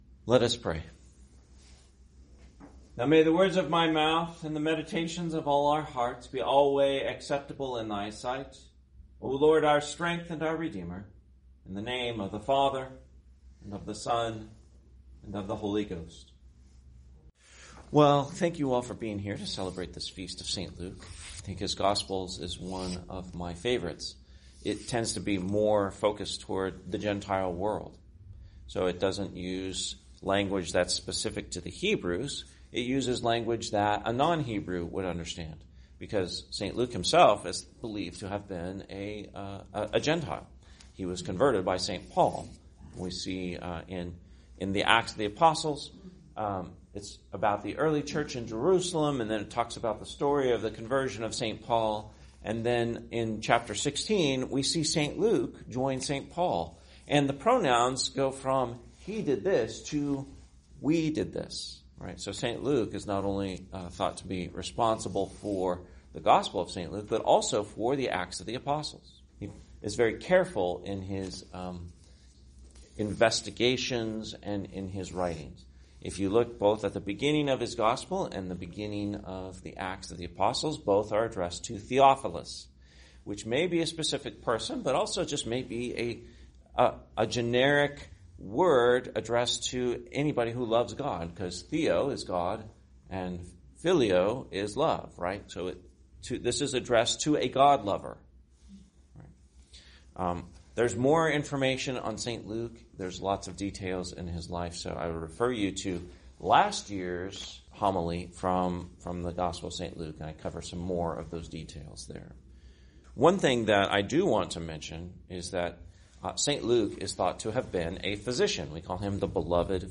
Homily for the Feast of St. Luke, 2025